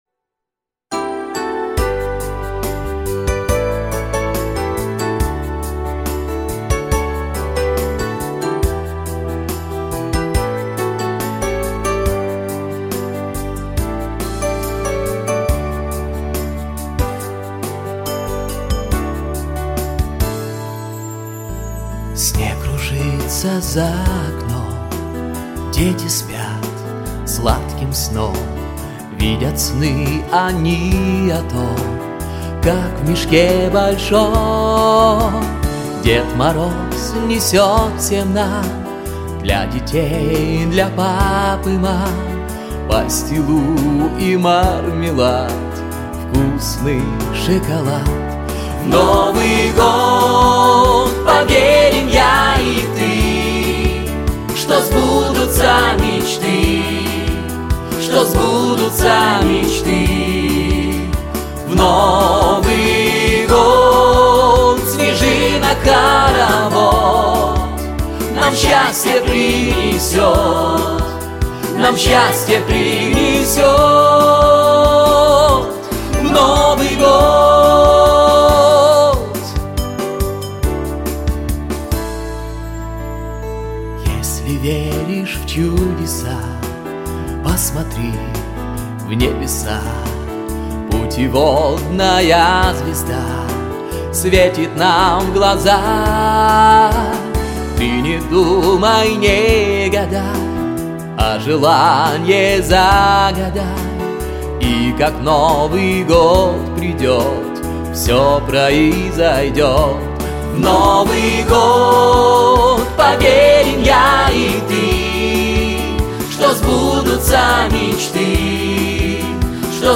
🎶 Детские песни / Песни на Новый год 🎄